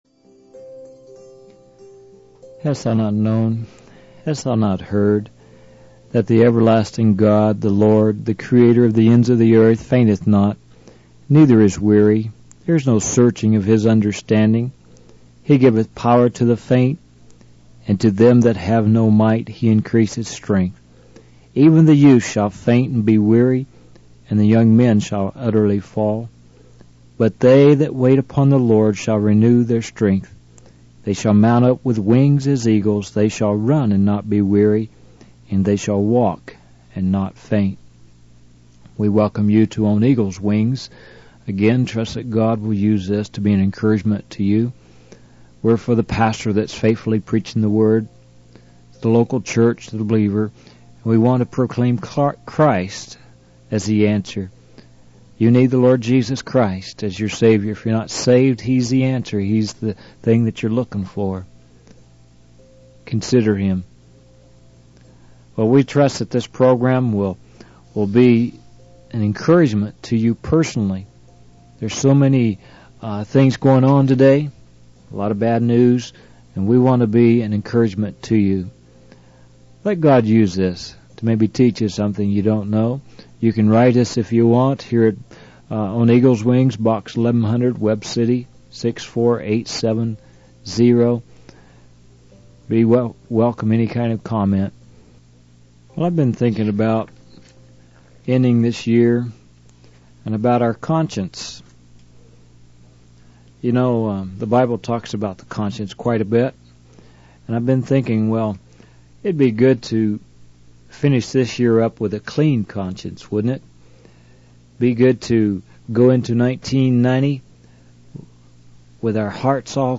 In this sermon, the preacher emphasizes the importance of having a good conscience before God and man. He mentions restitution guidelines that help believers make things right with others. The preacher highlights the need for genuine love, a pure heart, and a good conscience in the Christian life.